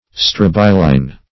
Search Result for " strobiline" : The Collaborative International Dictionary of English v.0.48: Strobiline \Strob"i*line\, a. Of or pertaining to a strobile; strobilaceous; strobiliform; as, strobiline fruits.